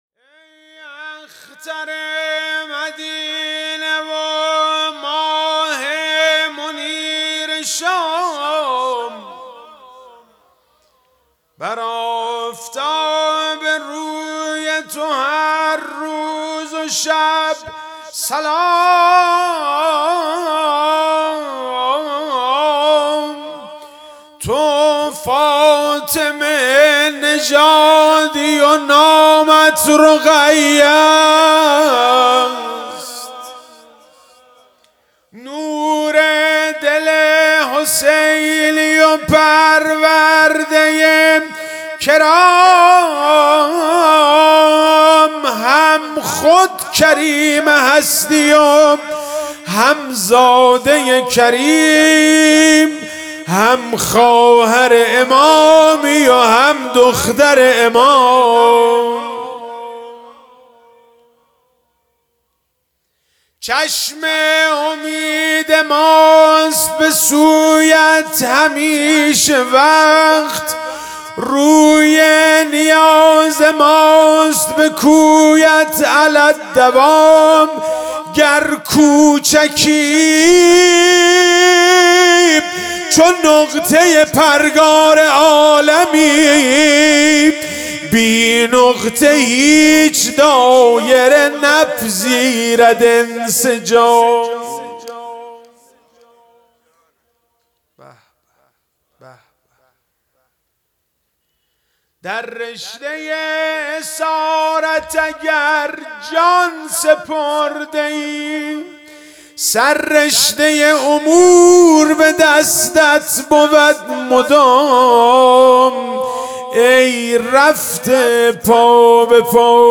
عزاداری ایام پایانی صفر1400